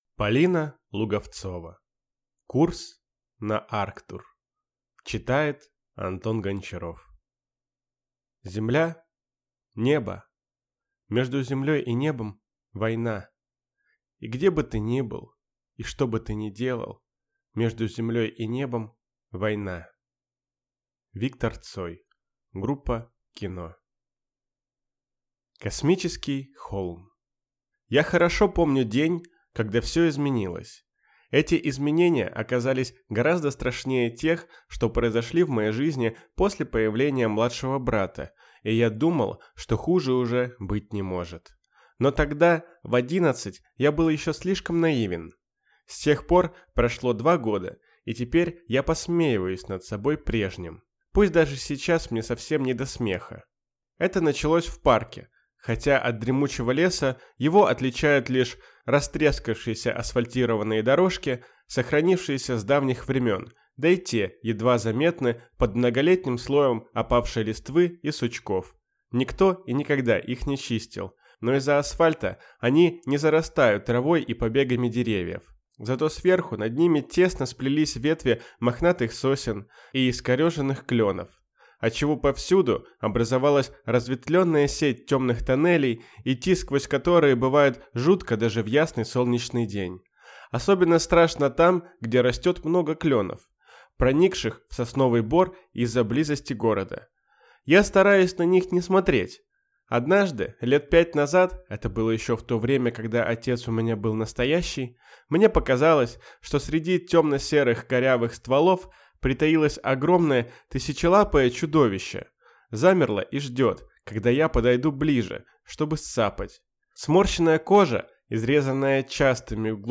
Аудиокнига Курс на Арктур | Библиотека аудиокниг